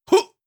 Effort Sounds
03. Effort Grunt (Male).wav